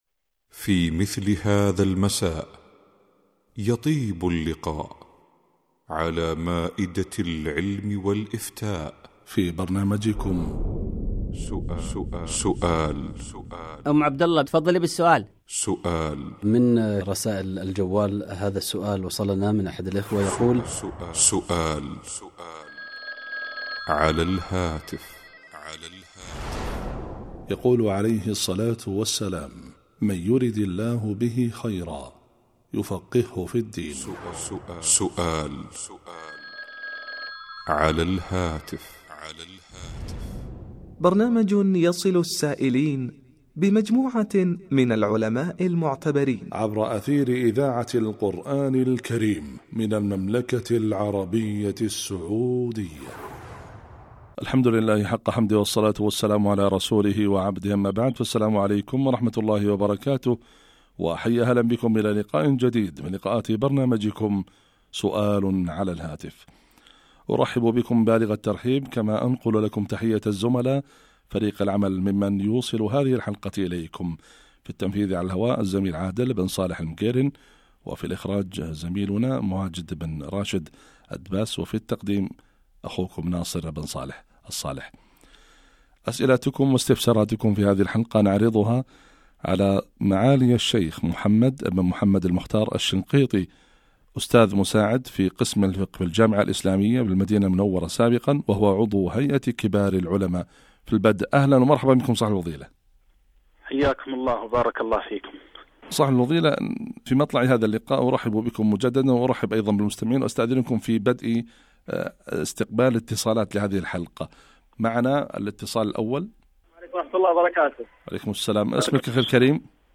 سؤال على الهاتف1
الفتاوى